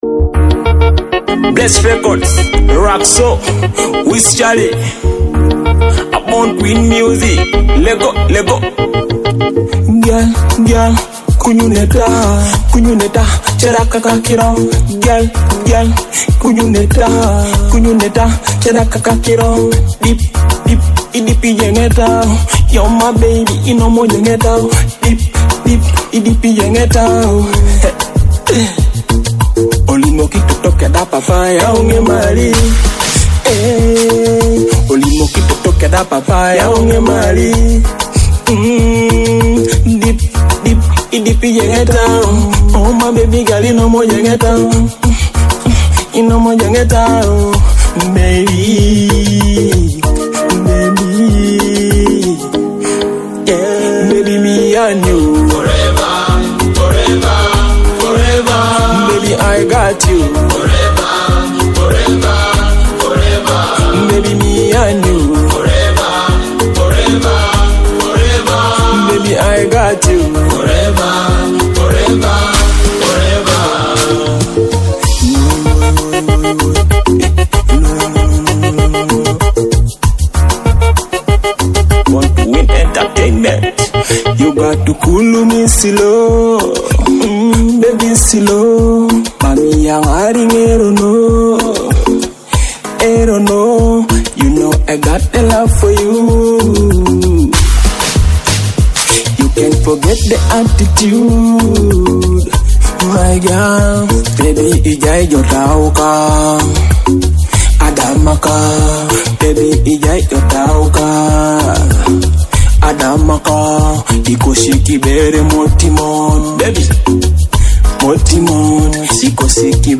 a soulful Teso love song in high-quality MP3.
love song
smooth romantic melodies, and soulful vibes